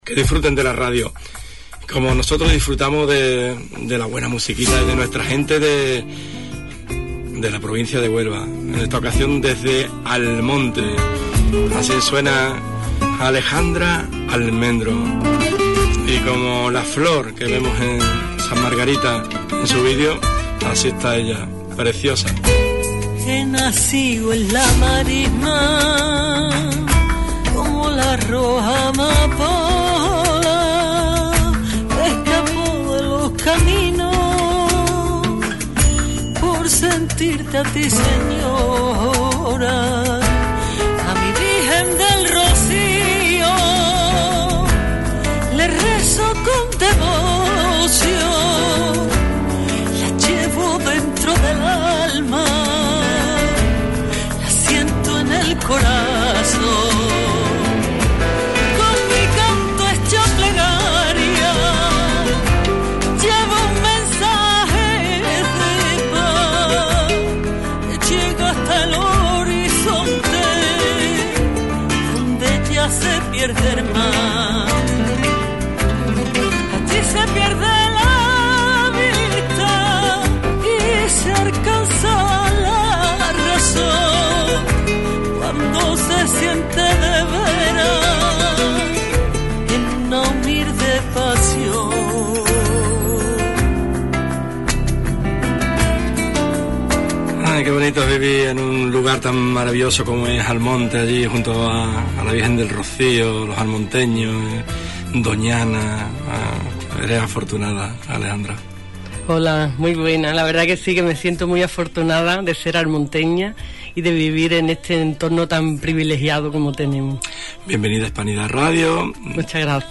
Tuvimos la suerte de tenerla en nuestro estudios y conocer de primera mano sus proyectos.